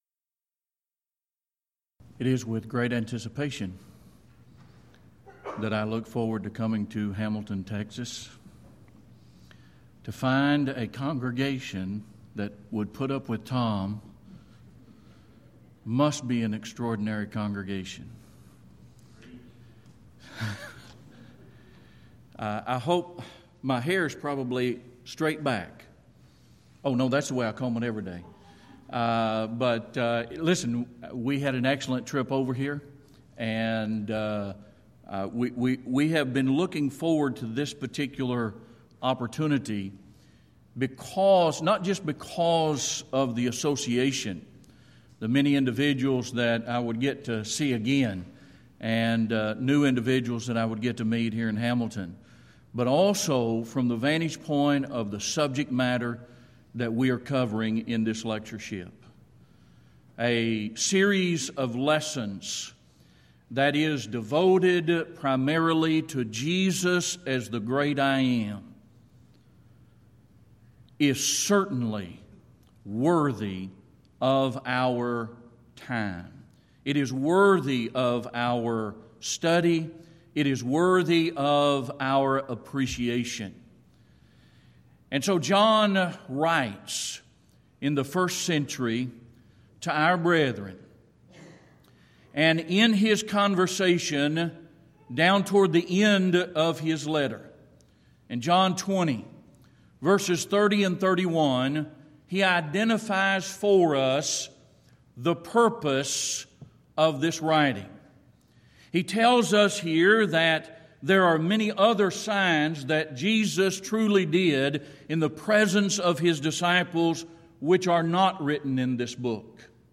Event: 4th Annual Back to the Bible Lectures Theme/Title: The I Am's of Jesus
this lecture